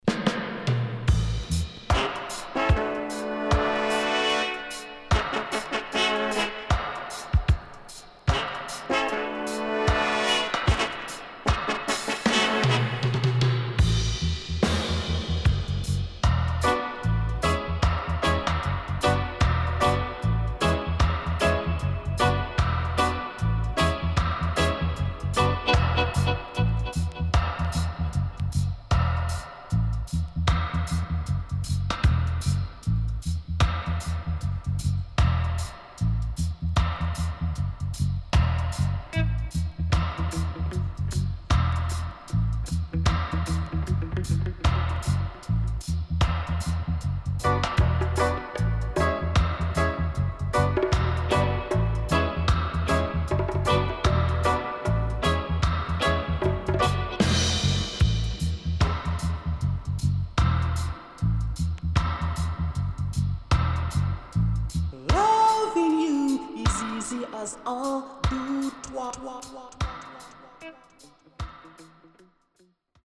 Sweet Roots Lovers & Dubwise